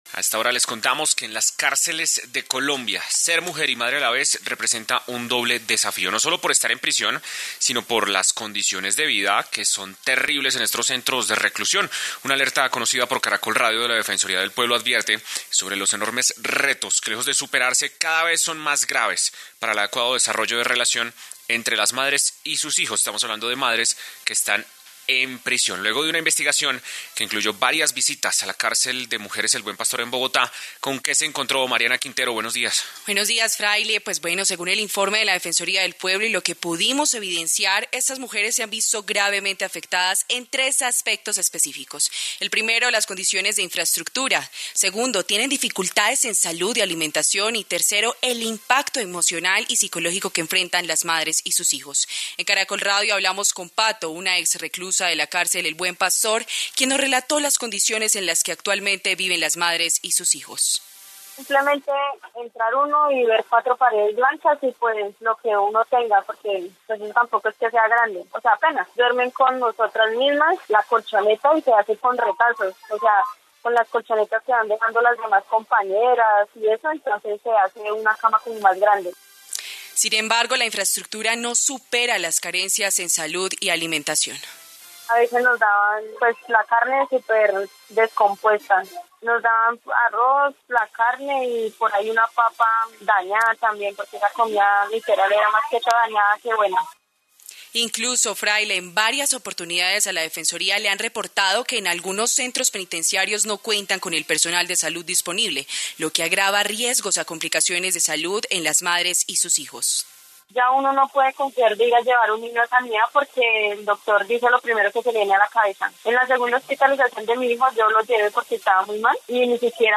Caracol Radio habló con estás mujeres y obtuvo testimonios que aseguran fallas en las condiciones de vida en centros penitenciarios del país.
Recopilamos testimonios de mujeres que han estado en prisión y han vivido el drama de estar embarazadas o tener sus hijos en las cárceles del país.